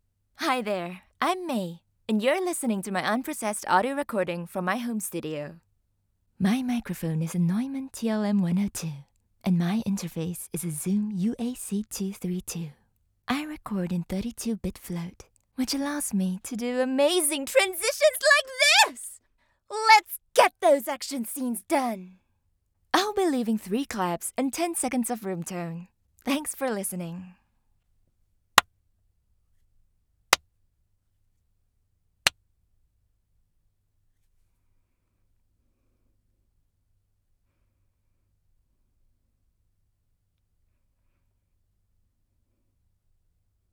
Home Studio Setup
• Microphone: Neumann TLM 102
• Audio Interface: Zoom UAC-232
• Booth: Professionally custom built 1.7m by 1.4m sound proofed and acoustically treated room